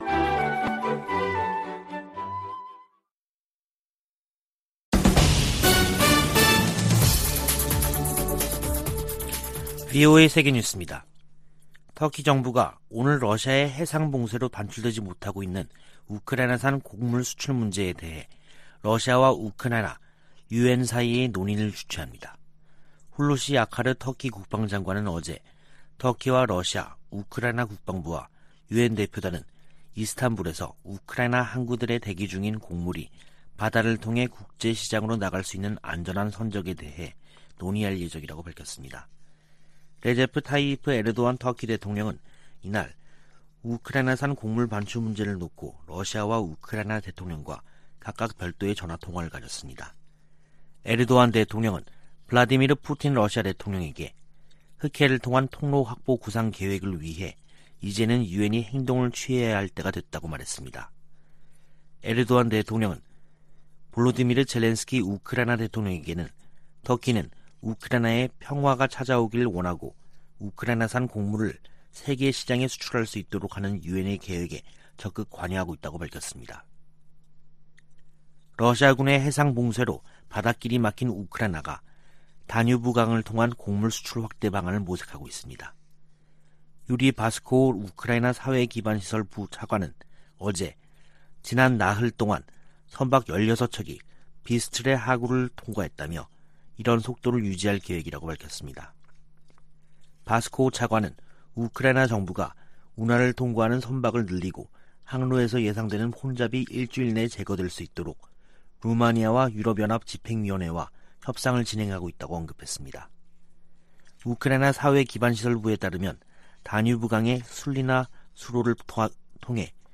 VOA 한국어 간판 뉴스 프로그램 '뉴스 투데이', 2022년 7월 13일 2부 방송입니다. 전임 도널드 트럼프 미국 행정정부에서 고위 관료를 지낸 인사들이 강력한 대북 제재로, 김정은 국무위원장이 비핵화의 길로 나오도록 압박해야 한다고 말했습니다. 미 국무부 선임고문이 한국 당국자들을 만나 양국 관계 강화와 국제 현안 협력 방안을 논의했습니다. 북한이 최근 방사포를 발사한 것과 관련해 주한미군은 강력한 미한 연합방위태세를 유지하고 있다고 밝혔습니다.